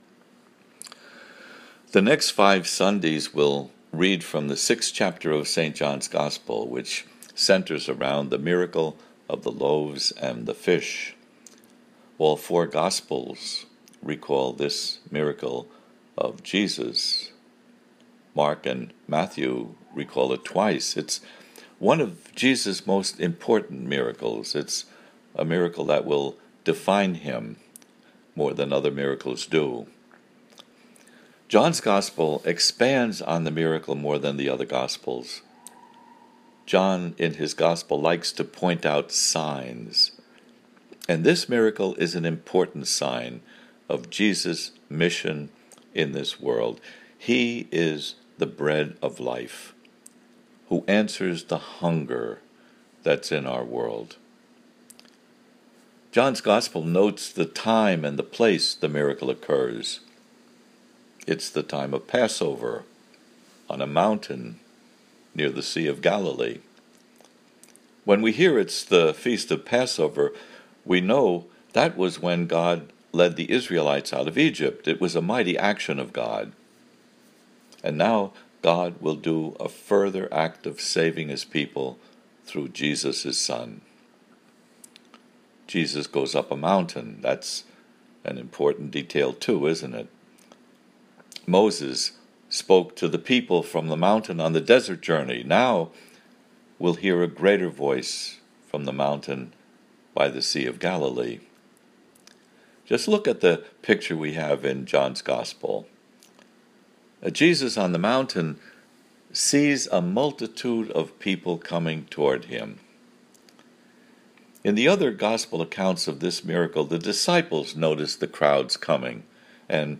Audio homily below: